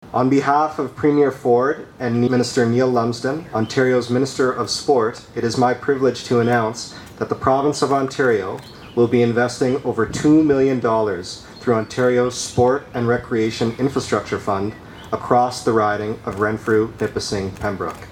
MPP Billy Denault was in Waterfront Park in Pembroke  Park today with a major funding announcement that will have a ripple effect right across the Ottawa Valley.